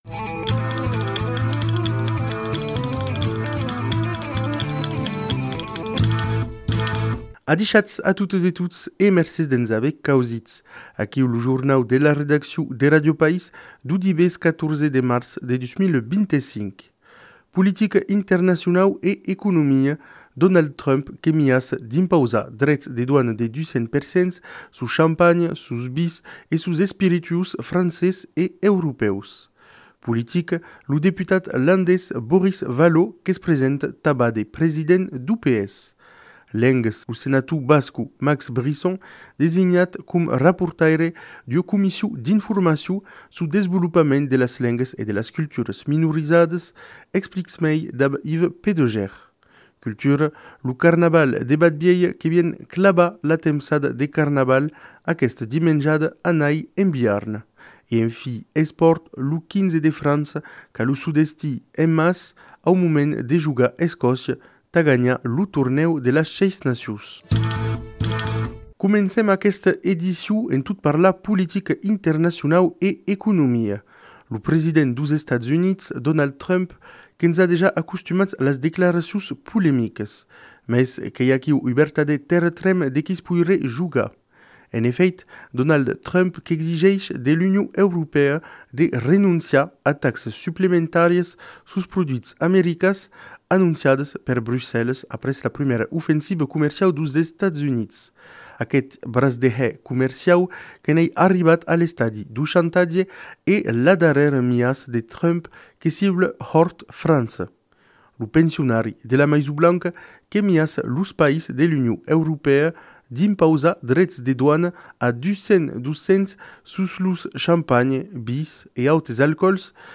Revista radiofonica realizada en partenariat amb Ràdio Occitania, Ràdio País, Ràdio Albigés, Ràdio Nissa Pantai e Ràdio Cultura del Brasil.
Una ora de dirècte de 12:00 a 13:00 !